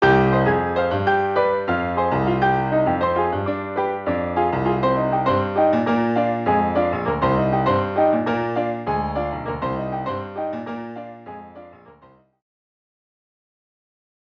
Im Cuba Mixer auf Old School stellen und dann hia: Anhänge CubaMafiaPiano2.mp3 395,7 KB · Aufrufe: 228